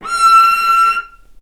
vc-E6-ff.AIF